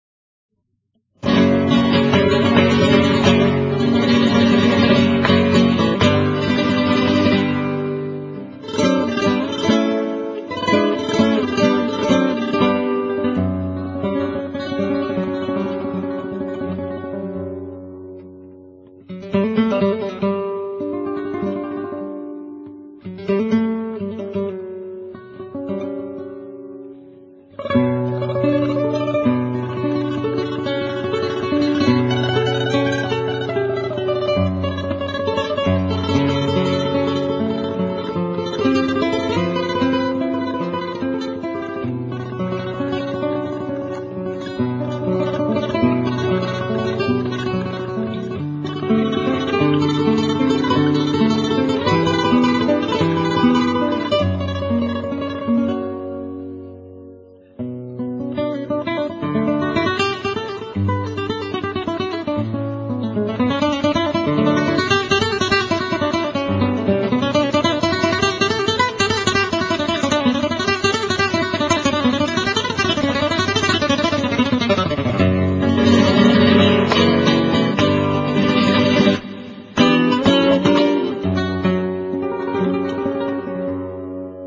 Son una maravilla de fidelidad al andaluz castizo, campero, que habla de verdad nuestra gente.
Resulta una verdadera pasado escuchar las mismas con el fondo del toque de una guitarra.